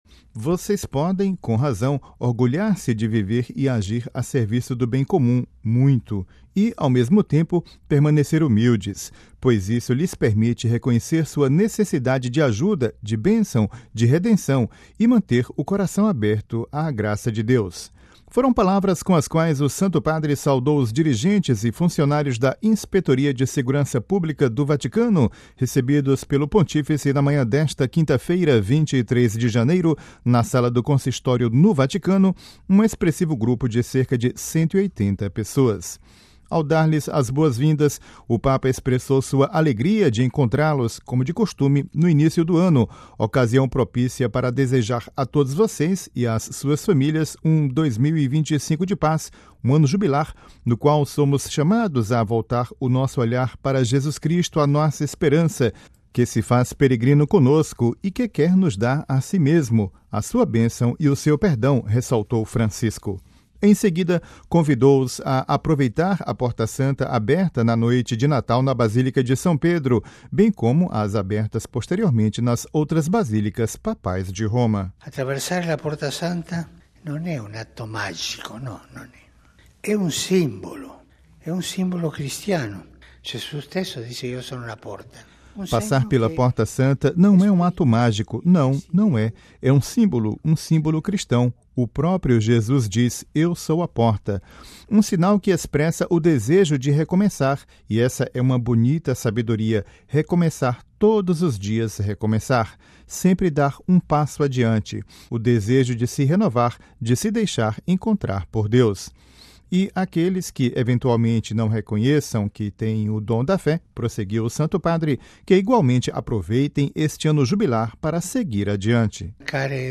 Ouça a reportagem com a voz do Papa Francisco e compartilhe